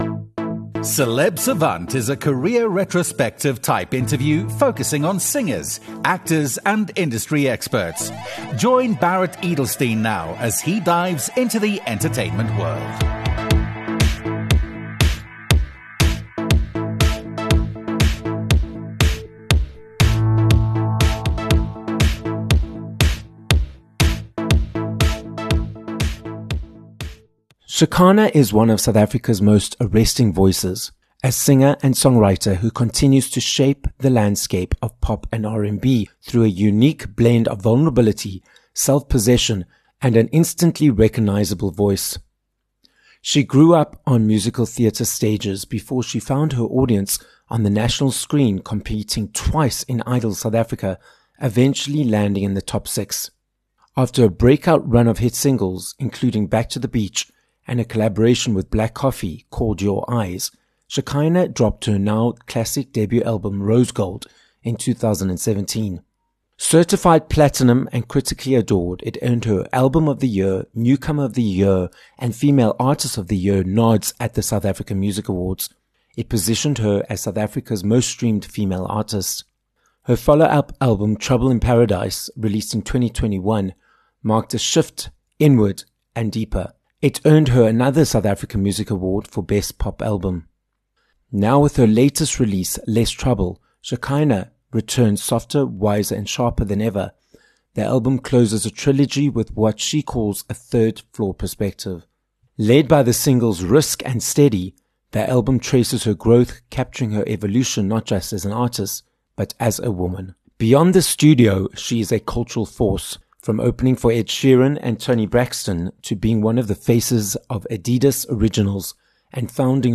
Shekhinah - a South African singer, songwriter, and multi SAMA award-winning artist - joins us live in studio for this episode of Celeb Savant. Shekhinah explains what it means to live the life of a pop star, her experience on Idols SA, why she takes her time when it comes to creating music, and what’s coming up next. This episode of Celeb Savant was recorded live in studio at Solid Gold Podcasts in Johannesburg, South Africa.